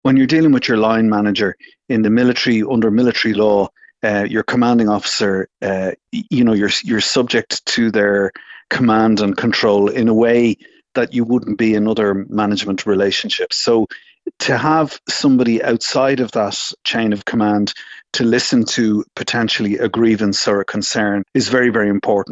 Senator and former Army Officer Tom Clonan says it’s a welcome step in the reform of the Defence Forces.